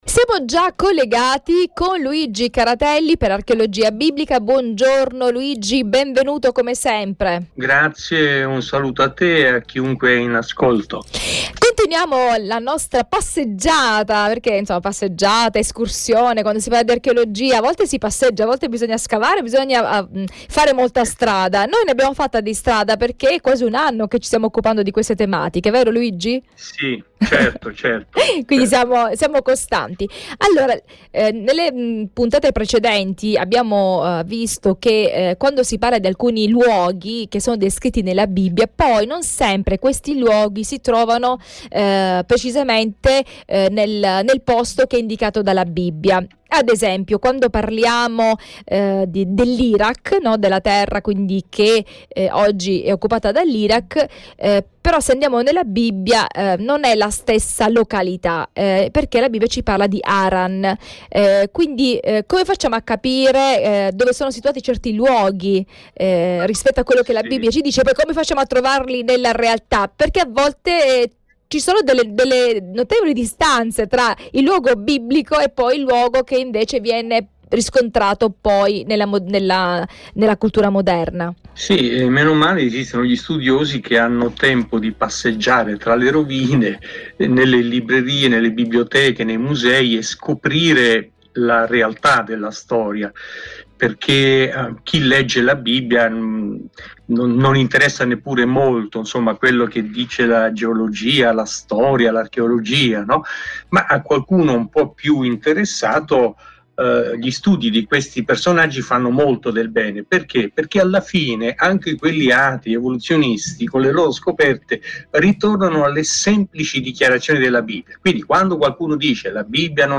dialogo